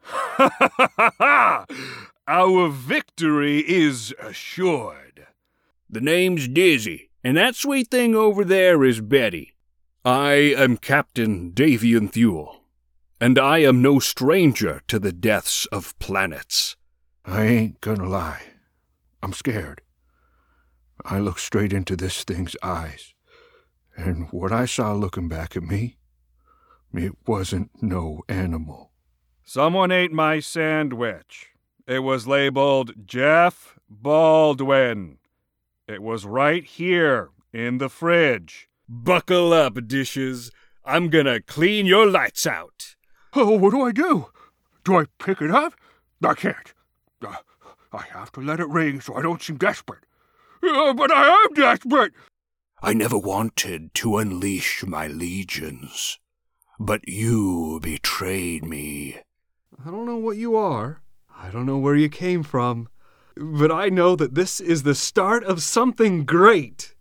VOICE ACTOR
Animation & Video Game Demo